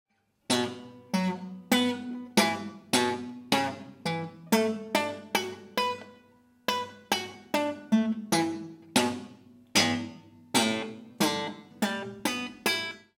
118. Pizzicato Bartok.m4v